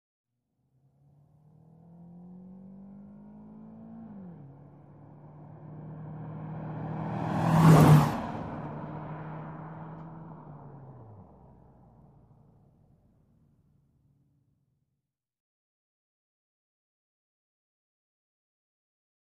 V-8 Car, Large; Medium Short In, By, Then Medium Short Out.